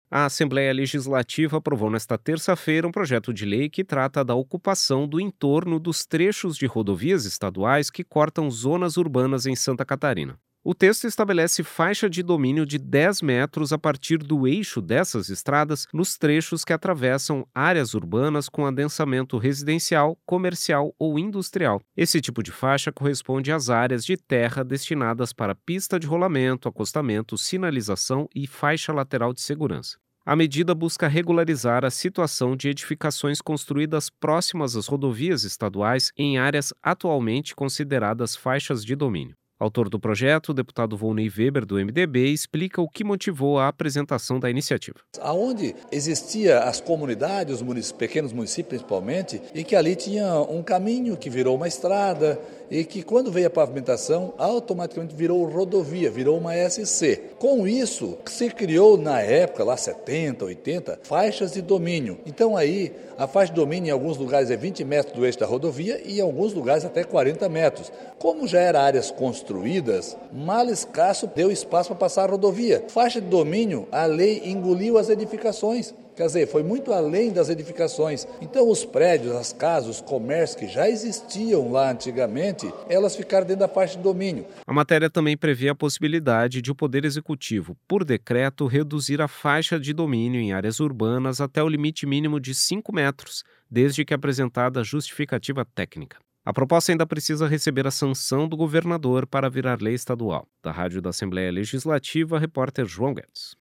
Entrevista com:
- Volnei Weber (MDB), autor do projeto.